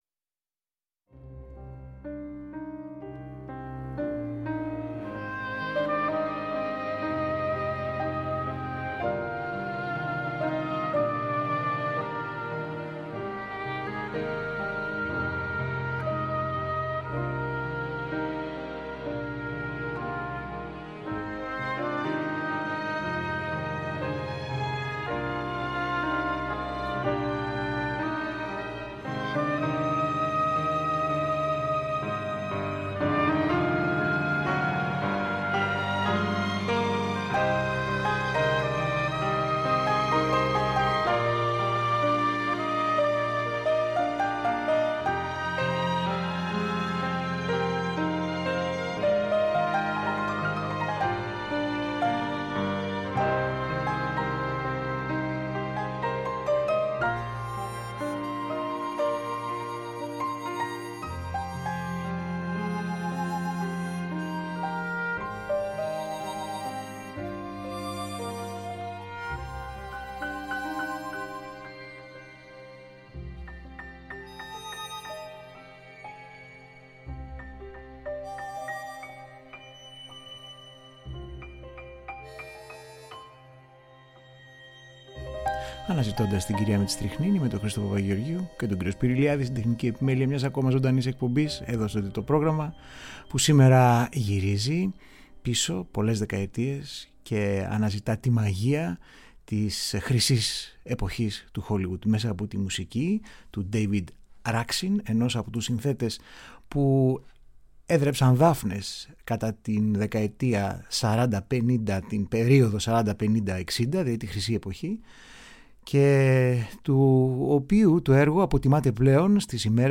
Ήχοι μιας άλλης εποχής, αυτής του αιώνιου απαράμιλλου στυλ και της επιτομής του κομψού και απέριττα πολυτελούς, απανταχού παρόντος στο παγκόσμιο design της δεκαετίας του 1950.